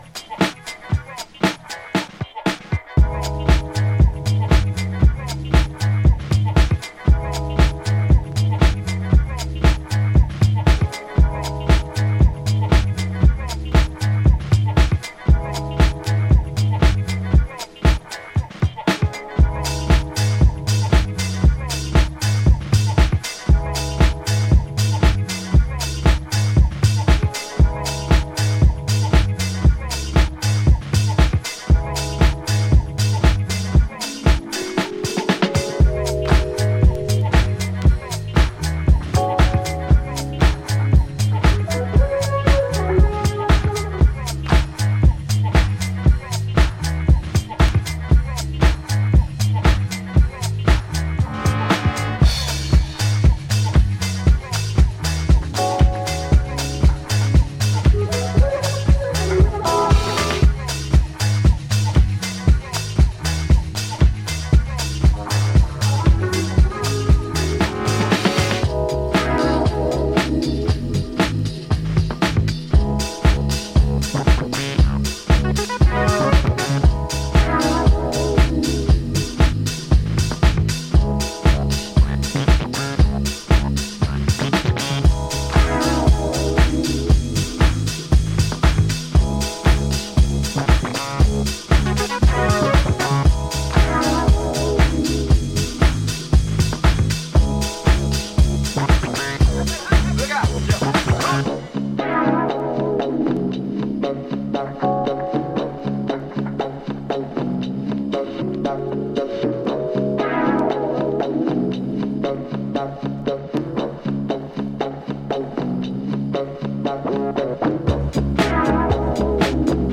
swirling synths and driving organic grooves